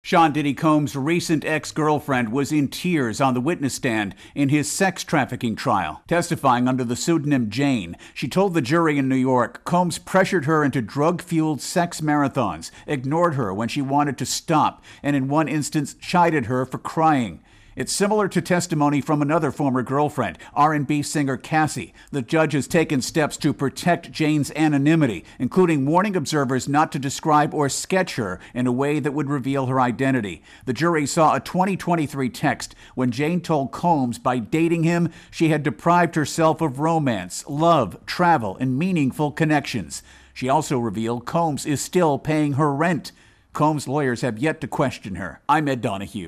reports on more emotional testimony in Sean "Diddy" Combs trial.